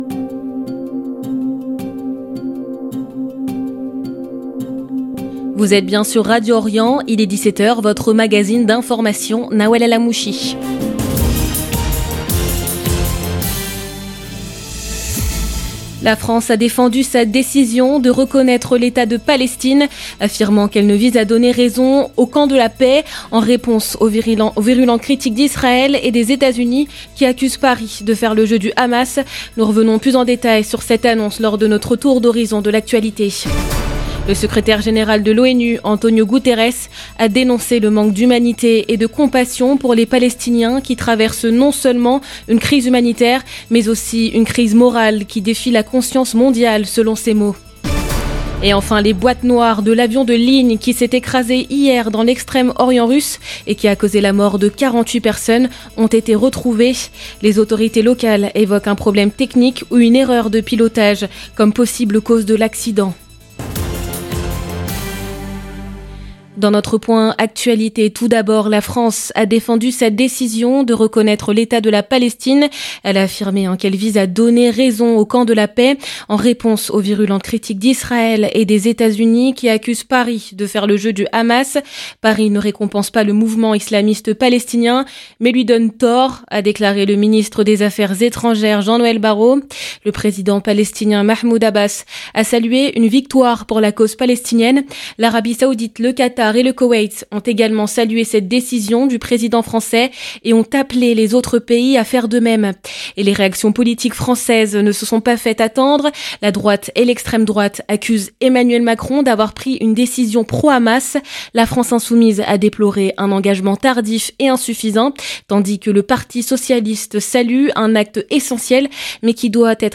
Magazine d'information de 17H